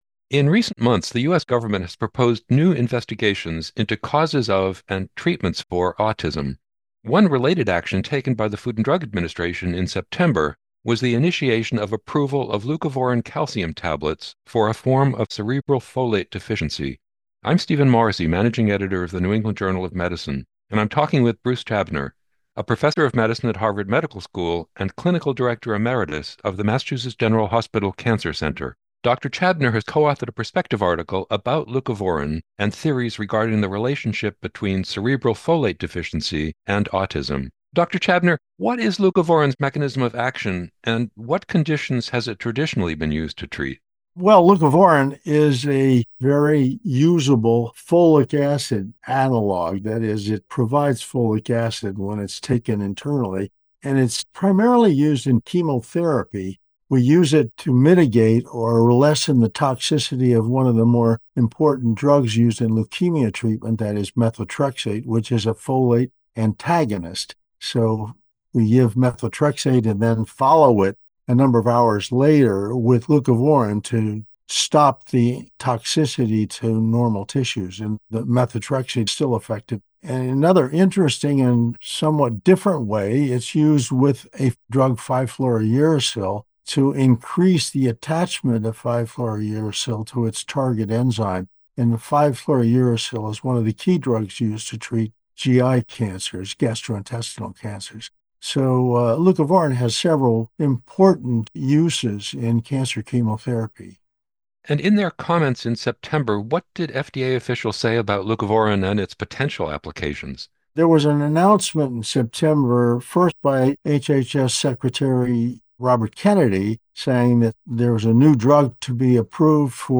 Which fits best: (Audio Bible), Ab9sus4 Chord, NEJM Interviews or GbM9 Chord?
NEJM Interviews